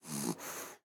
sounds / mob / fox / sleep2.ogg